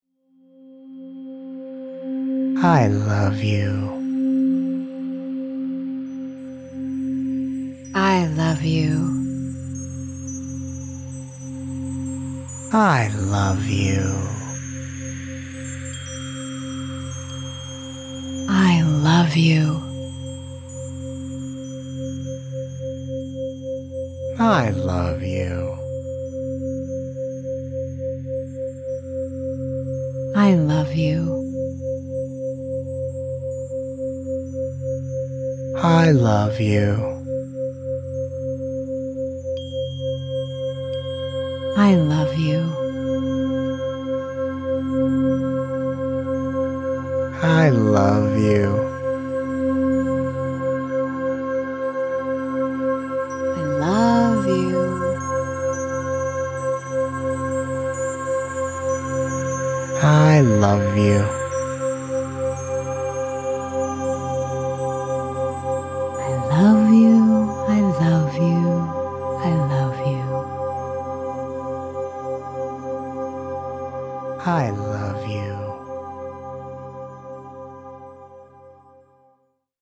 The Solfeggio frequencies used in these recordings were consciously chosen to vibrate at 528Hz, the love frequency of the heart, to allow an experience of deep healing and heart opening so that love may be given and received freely.
The subtle and powerful movement of sound maximizes the relaxation of the nervous system and the balancing of the right and left hemispheres of the brain.
This will maximize and deepen the relaxation response.